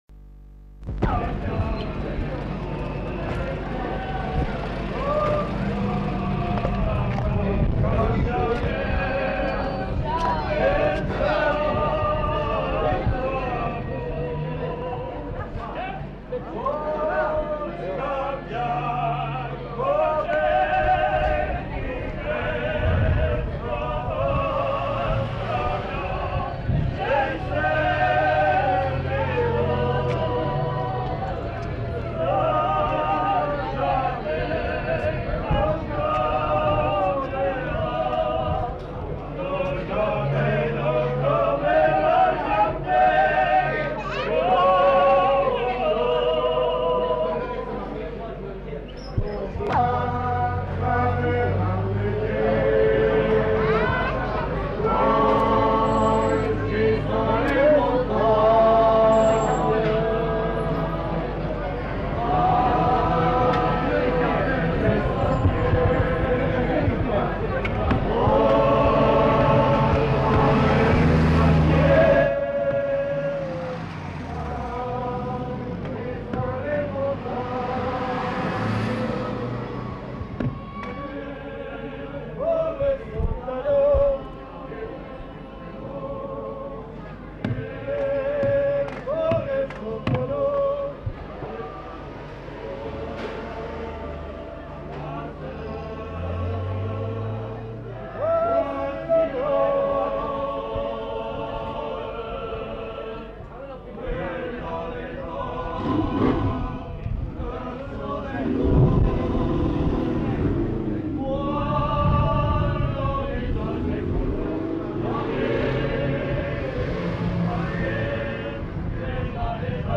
Aire culturelle : Béarn
Lieu : Bielle
Genre : chant
Type de voix : voix d'homme
Production du son : chanté
Descripteurs : polyphonie